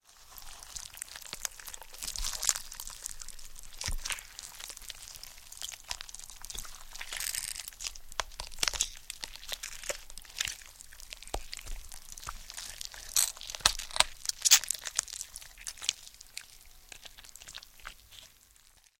На этой странице собраны мрачные и реалистичные звуки расчленения и вскрытия тела. Коллекция включает аудиоэффекты для создания атмосферы хоррора: хруст костей, скрип скальпеля, всплески жидкостей.